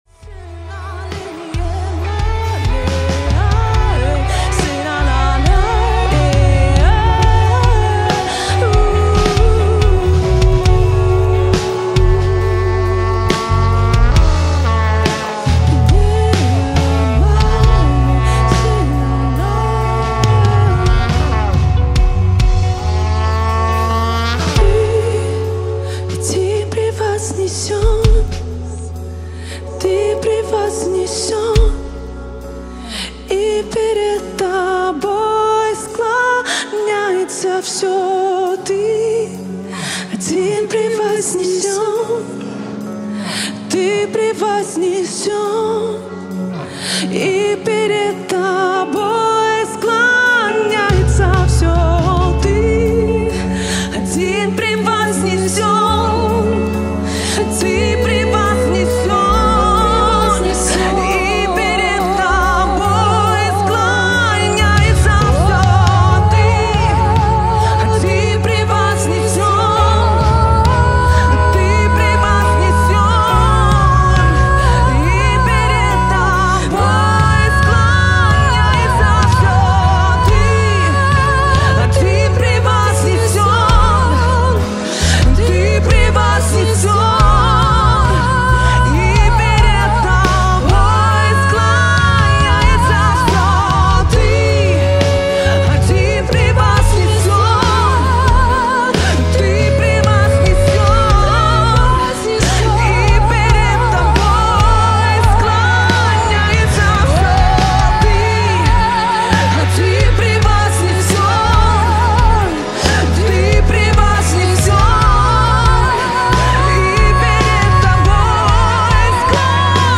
2024 single